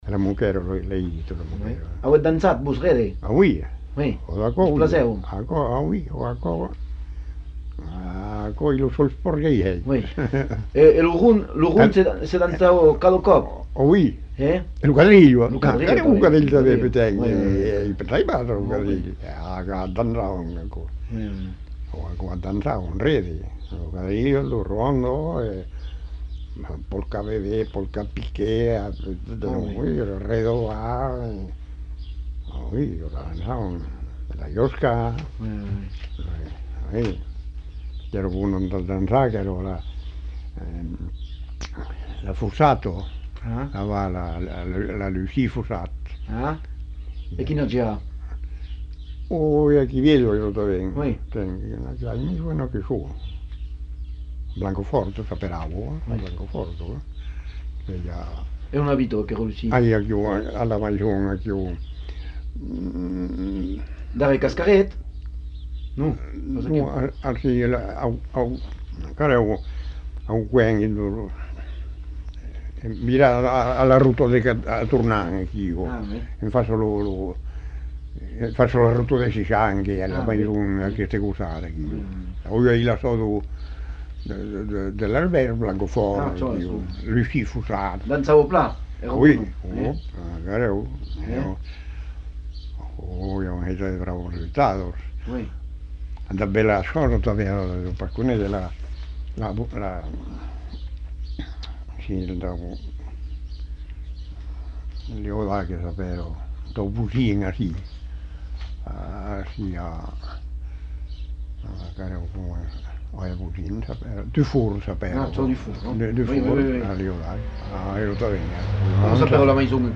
Lieu : Simorre
Genre : témoignage thématique